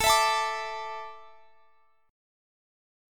Listen to Asus4 strummed